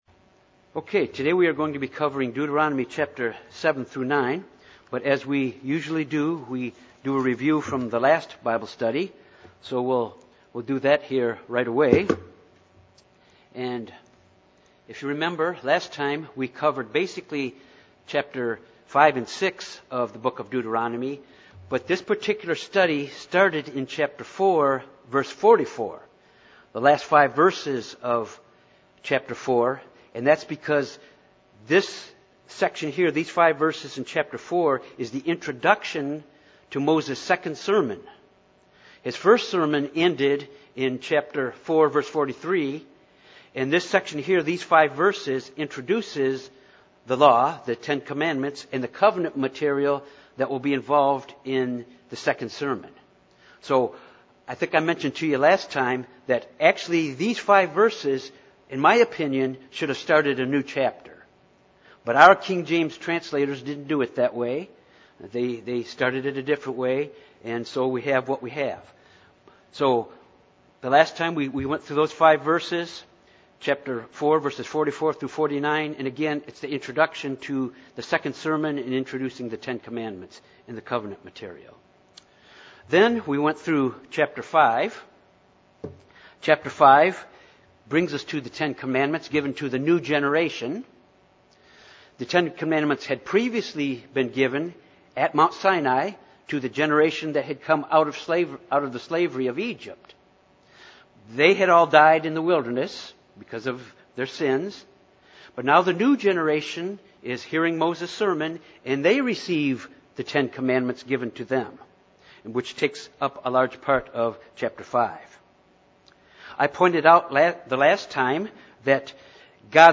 This Bible study continues with the “second sermon” given by Moses to the children of Israel just prior to their entering into the Promised Land. Chapter 7 begins by testing Israel’s fidelity to God.